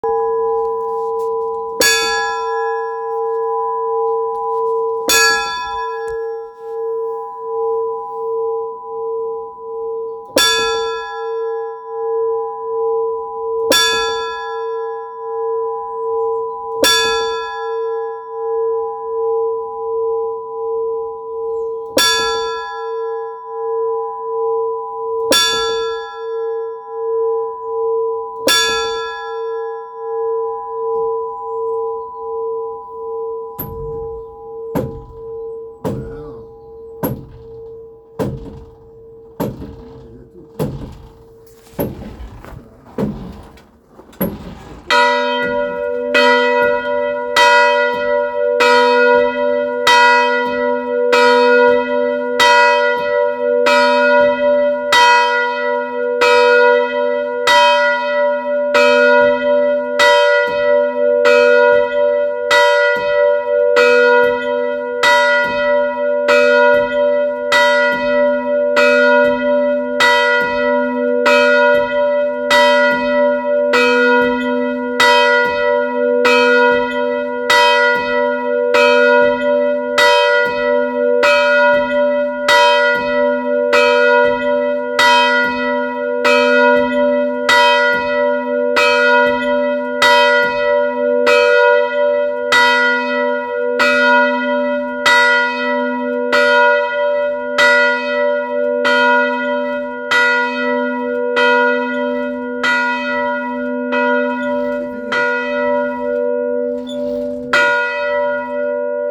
cloche n°2 - Inventaire Général du Patrimoine Culturel